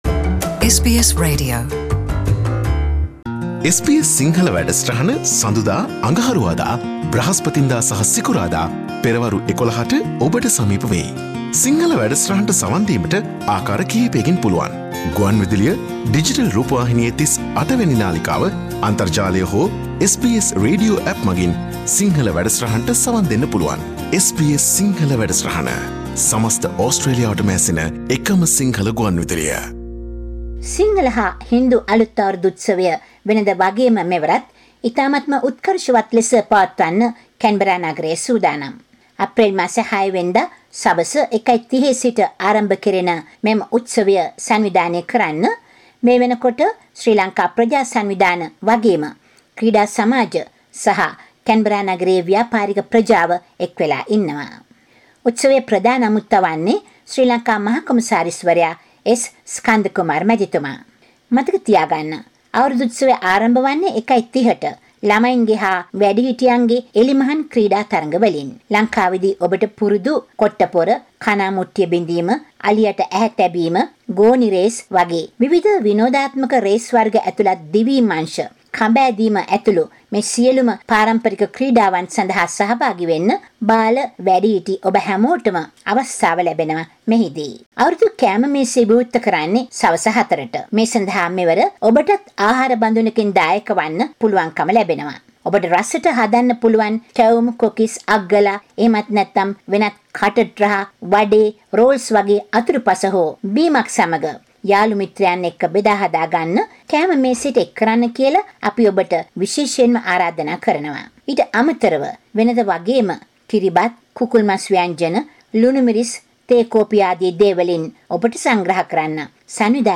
කැන්බරා නුවර සිංහල අලුත් අවුරුදු සැමරෙන ආකාරය පිළිබඳව ගෙන එන වාර්තාව